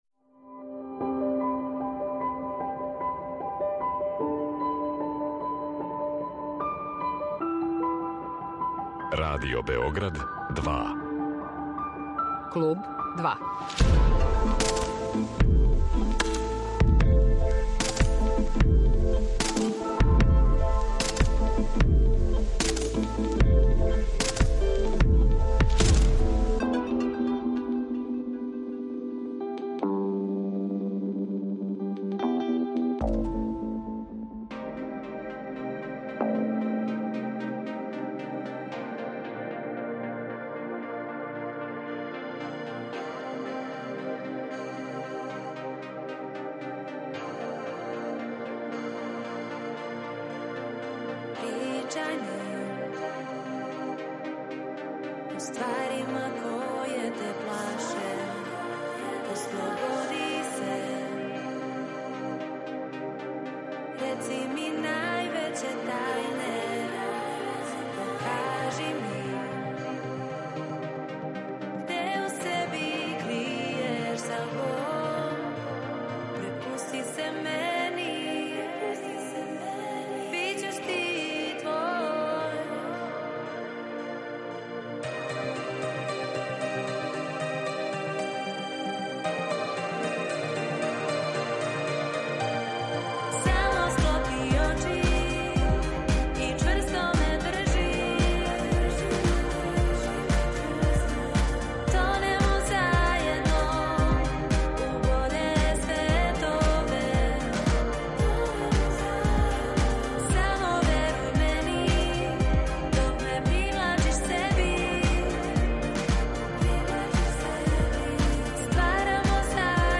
Инспириране synth, dream и brit-pop жанровима, као и визуелним референцама филма "Melancholia" Ларса фон Трира, њихова жеља и визија песама били су сањиви, космички синтисајзерски звукови који код слушалаца изазивају сладак осећај меланхолије.